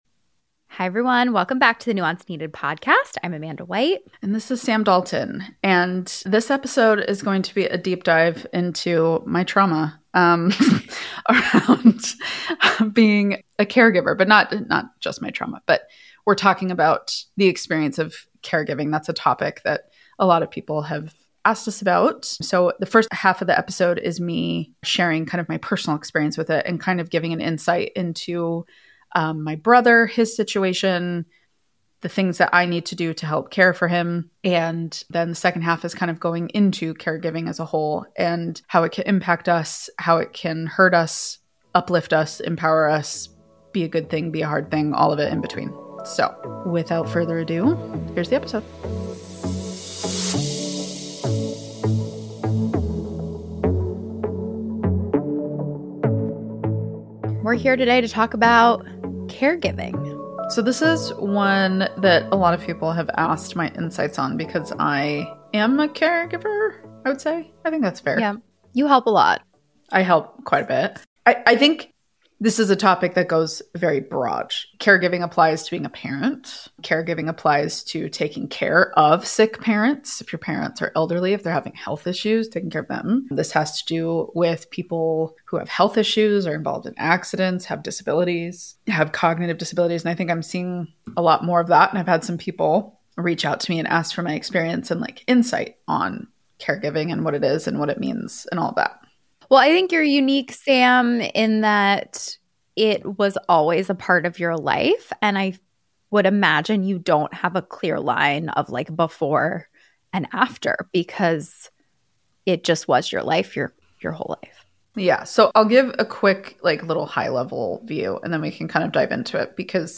In conversation, we tackle: